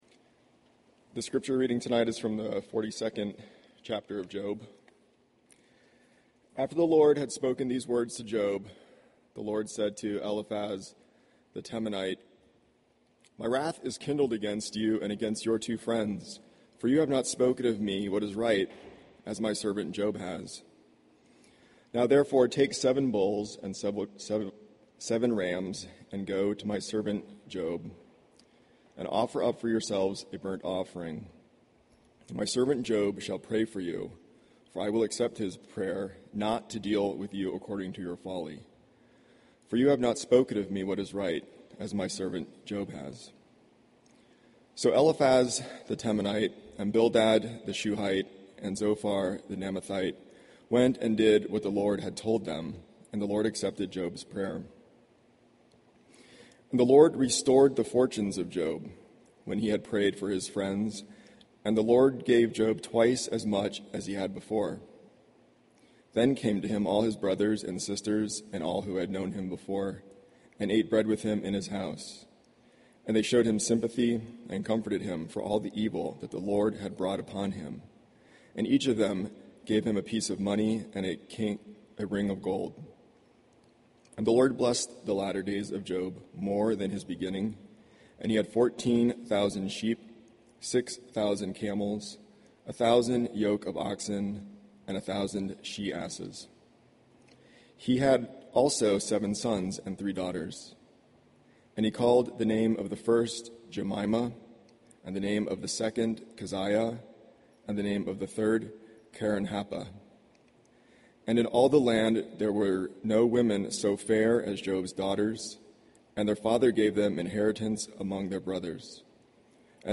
Sermons .